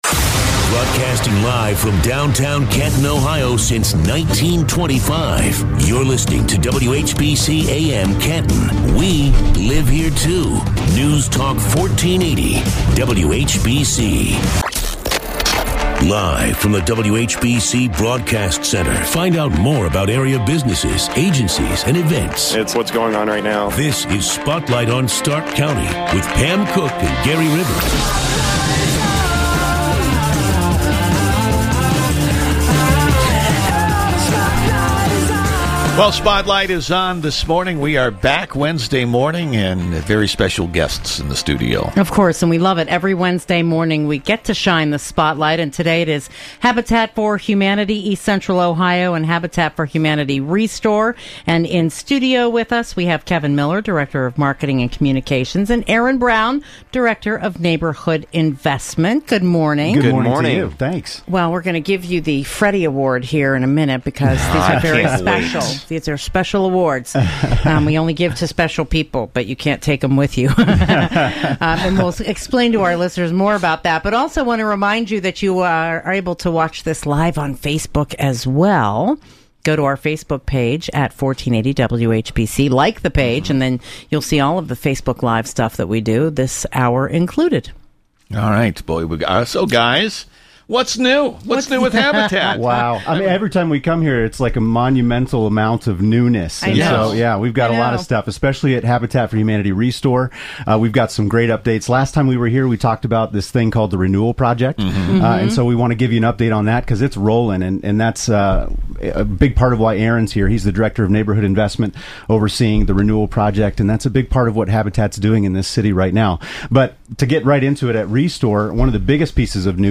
Radio: WHBC Spotlight, February 15, 2017
We’re broadcasting the mission of Habitat for Humanity East Central Ohio and Habitat for Humanity ReStore over the radio airwaves!
Sit back, click play, and listen in to the Spotlight conversation!